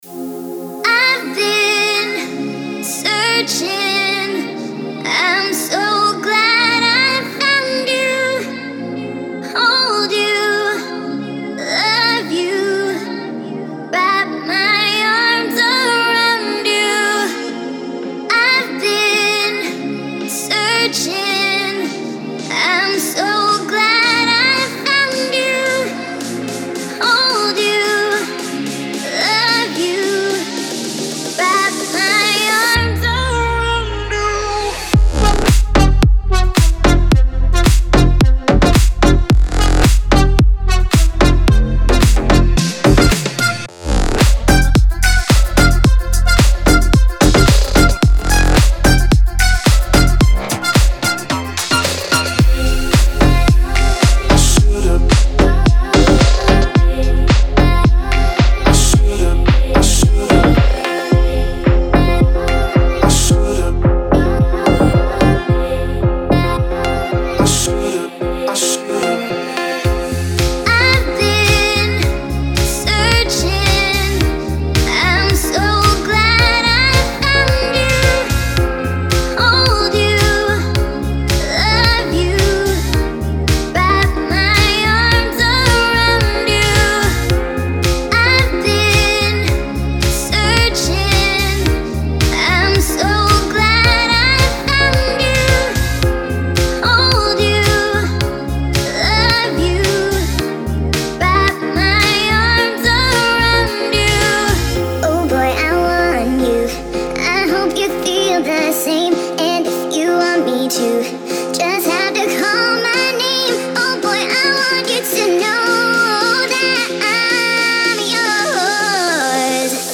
атмосферная поп-баллада